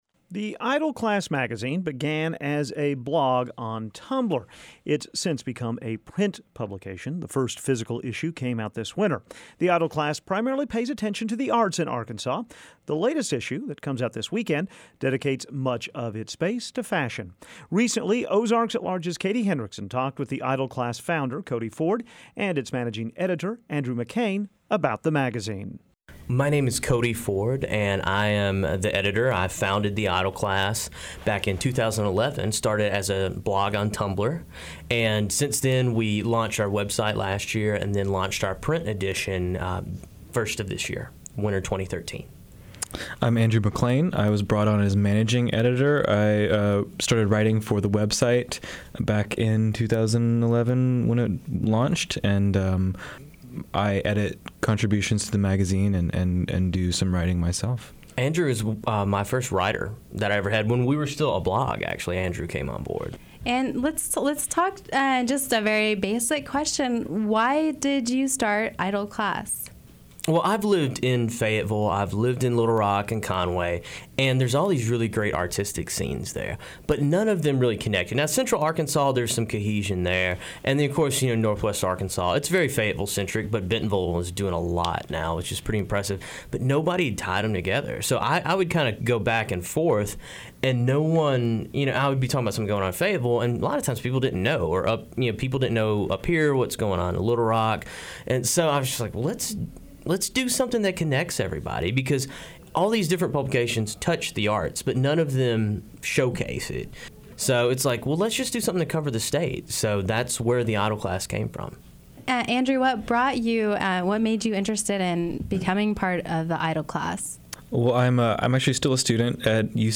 stopped by the studio recently to discuss both the challenges and rewards of running a magazine for the arts in Arkansas.